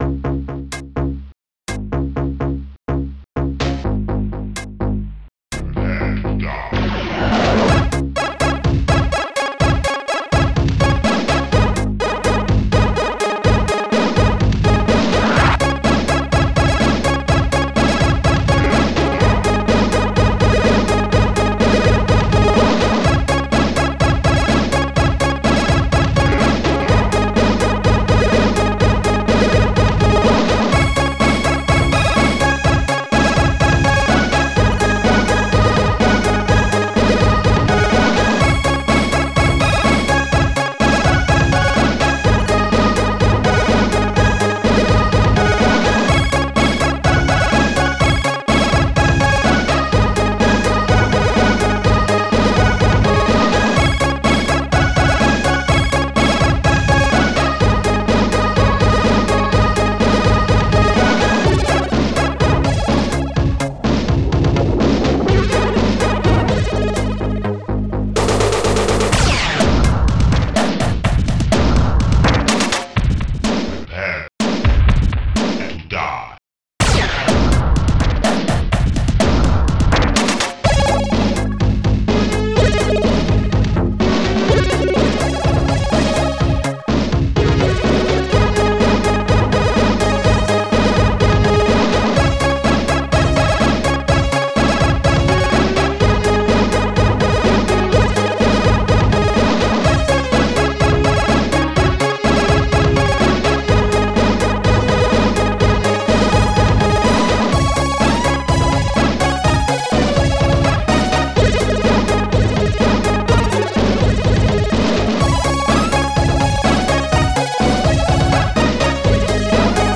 st-02:bassdrum23
st-02:snare14
st-06:CordPiano
st-04:laserexplo
st-06:shortstring
st-06:Siren